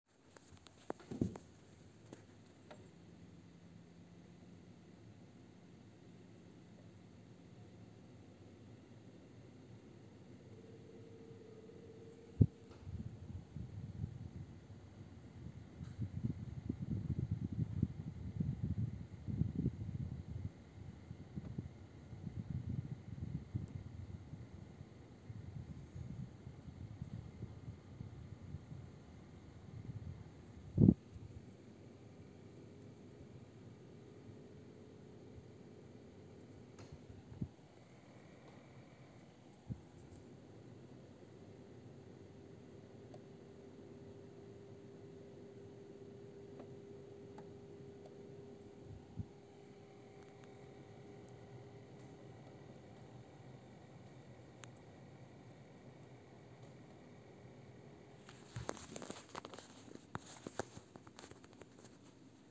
Est-ce que quelqu'un saurait à quoi pourrait être dû ce bruit qui se produit lorsque la clim se met à chauffer ?
En gros ça fait un genre de sifflement au début suivi d'un bruit de moteur.
Bruit climatiseur au démarrage
Désolé, on n'entend pas grand chose sur votre enregistrement.